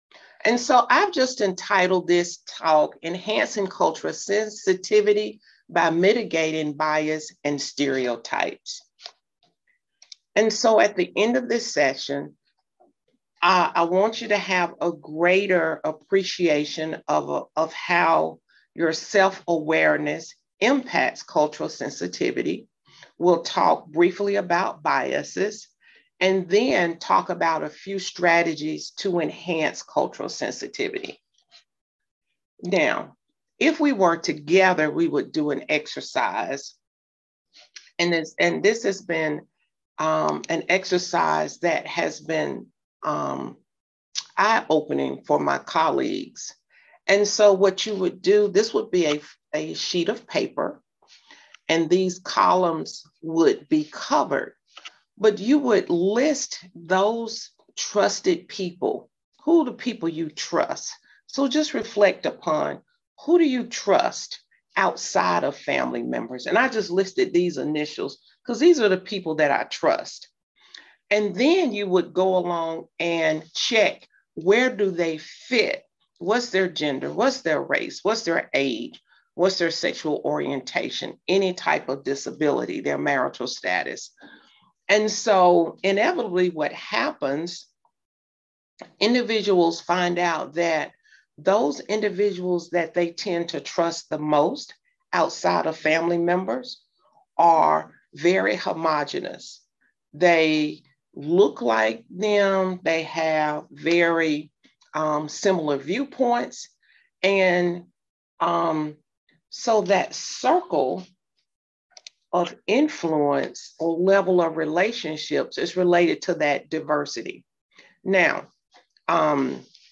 Training webinar or voice-over-PowerPoint [coming soon] Training webinar on culturally sensitive patient care Implementation of GUIDED-HF training [PDF] Self-care coach resources: For more information, visit GUIDED-HF Coaching Resources .